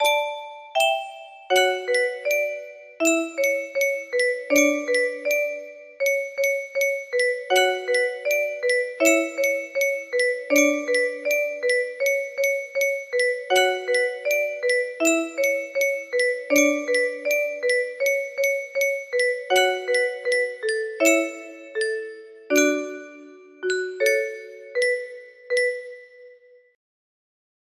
final chorus